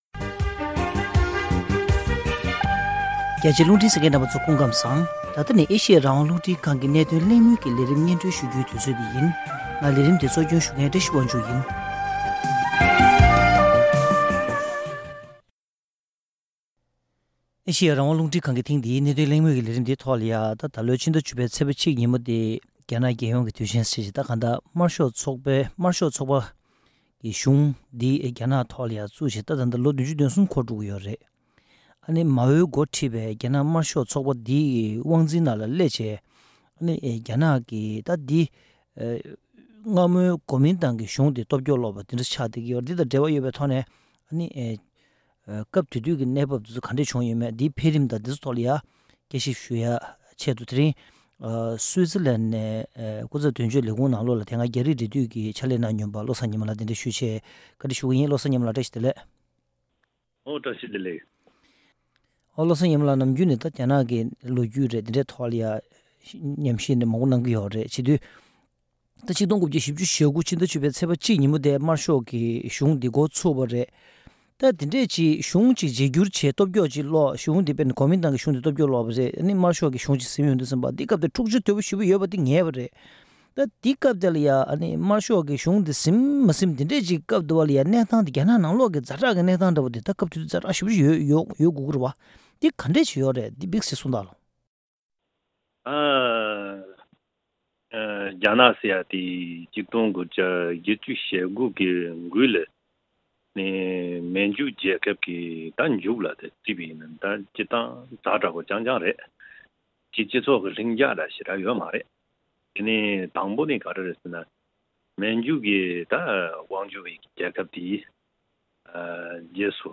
༡༩༤༩ ཟླ་ ༡༠ ཚེས་ ༡ གོང་ལ་རྒྱ་ནག་ནང་གི་དམར་ཤོག་ཚོགས་པ་དང་གོ་མིན་ཏང་དབར་གྱི་འཁྲུགས་རྩོད་སྐོར་གླེང་མོལ།